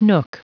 Prononciation du mot nook en anglais (fichier audio)
Prononciation du mot : nook